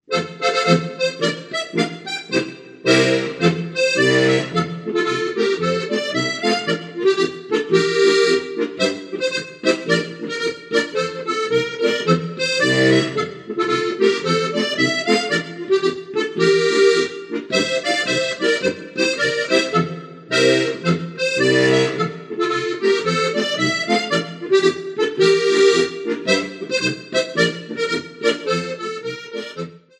Besetzung: Okarina und Akkordeon